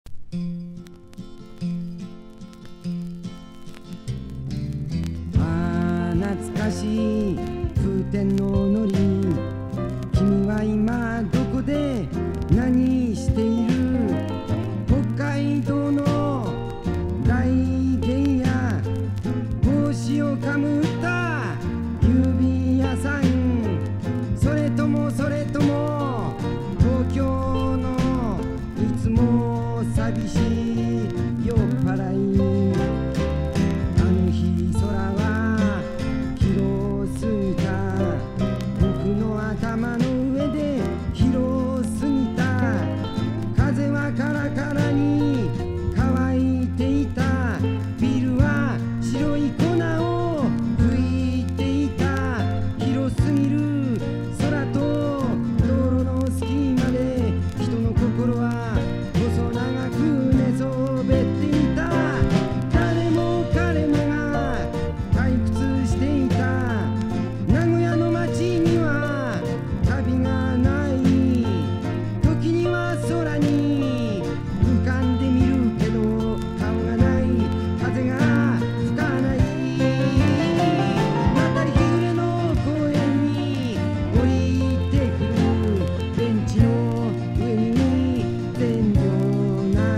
60-80’S ROCK# SSW / FOLK
(薄いスリキズ有り、チリノイズ有る箇所有り)